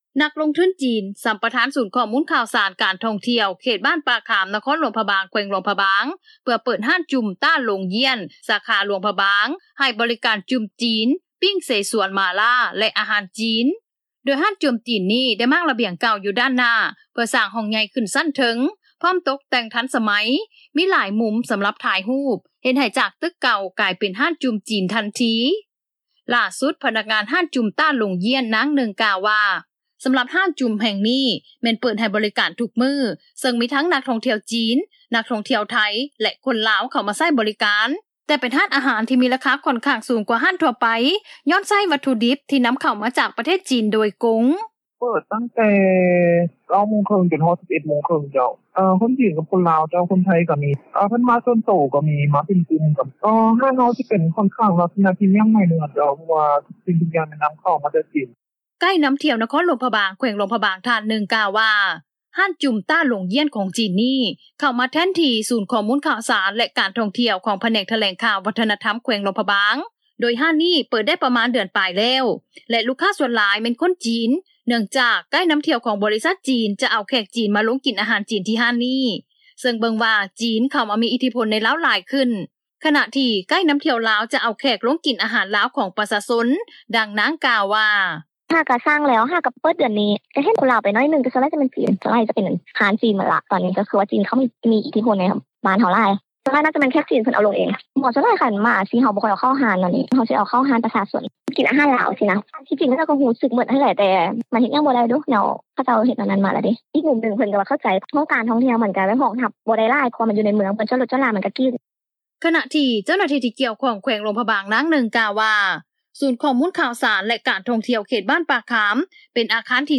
ໄກ້ນໍາທ່ຽວ ນະຄອນຫຼວງພຣະບາງ ແຂວງຫຼວງພຣະບາງ ທ່ານໜຶ່ງ ກ່າວວ່າ ຮ້ານຈຸ່ມ ຕ້າ ຫຼົງ ຢ້ຽນ ຂອງຈີນ ນີ້ ເຂົ້າມາແທນທີ່ ສູນຂໍ້ມູນຂ່າວສານ ແລະການທ່ອງທ່ຽວ ຂອງພະແນກຖະແຫຼງຂ່່າວ ວັດທະນະທໍາ ແຂວງຫຼວງພຣະບາງ ໂດຍຮ້ານນີ້ ເປີດໄດ້ປະມານ ເດືອນປາຍແລ້ວ ແລະ ລູກຄ້າ ສ່ວນຫຼາຍ ແມ່ນຄົນຈີນ ເນື່ອງຈາກ ໄກ້ນໍາທ່ຽວ ຂອງບໍລິສັດຈີນ ຈະເອົາແຂກມາ ລົງກິນເຂົ້າທີ່ຮ້ານນີ້ ເຊິ່ງເບິ່ງວ່າ ຈີນ ເຂົ້າມາມີອິດທິພົນ ໃນລາວ ຫຼາຍຂຶ້ນ, ຂະນະທີ່ ໄກ້ນໍາທ່ຽວລາວ ຈະເອົາແຂກ ລົງກິນອາຫານລາວ ຂອງປະຊາຊົນ, ດັ່ງນາງ ກ່າວວ່າ: